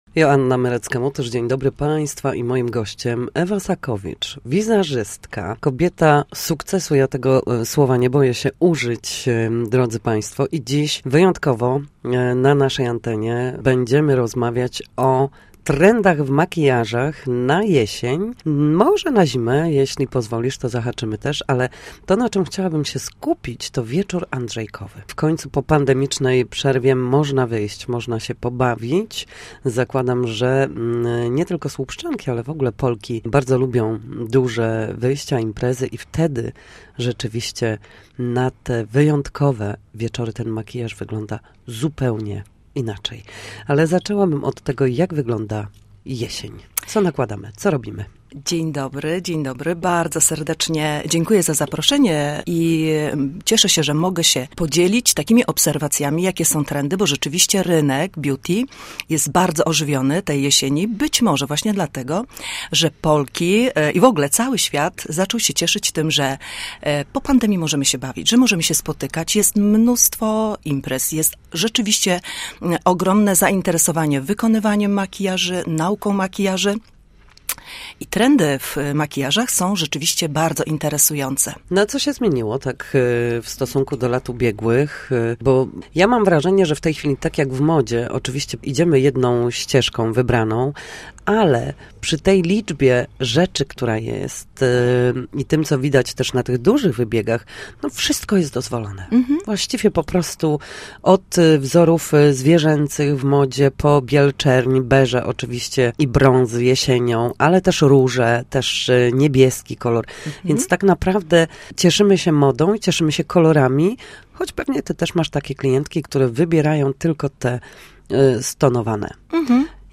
Gość Dnia Studia Słupsk trendy